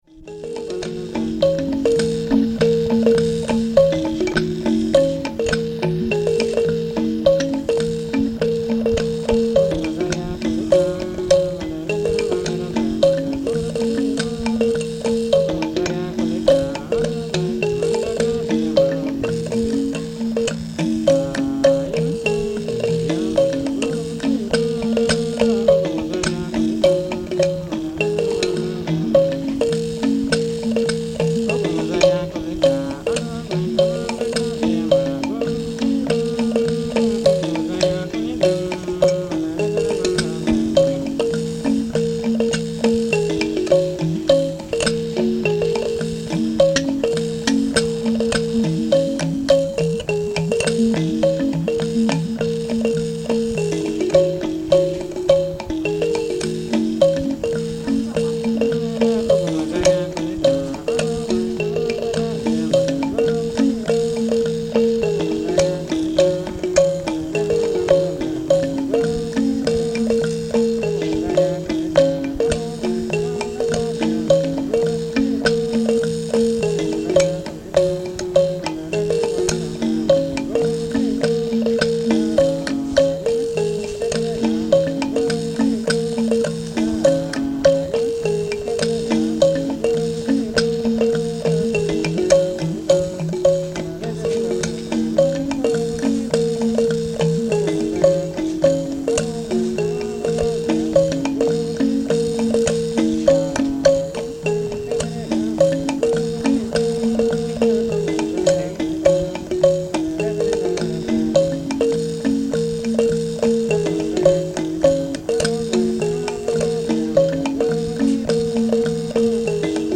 Sanza (lamellophone) music
From the sound collections of the Pitt Rivers Museum, University of Oxford, being from a collection of cassette tape recordings of music and spoken language (principally Laarim)